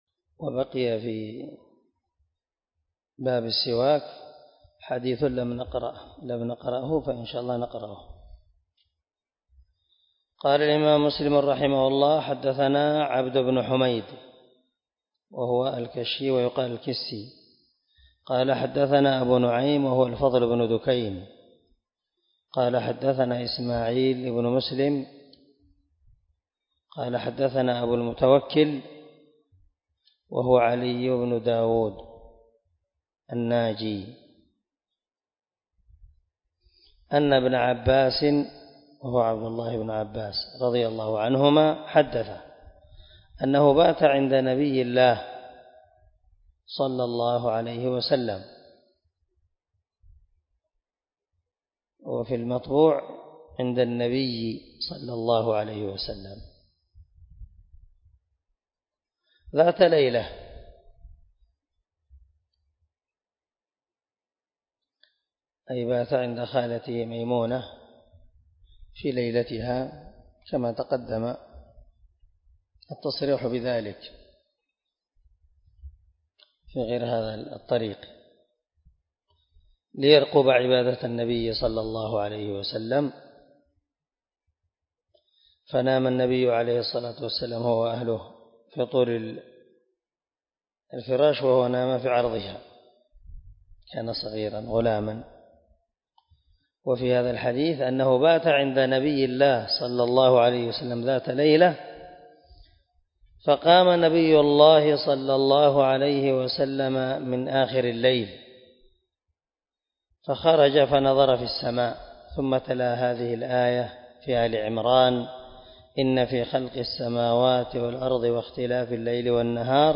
192الدرس 20 من شرح كتاب الطهارة حديث رقم ( 256 ) من صحيح مسلم
دار الحديث- المَحاوِلة- الصبيحة.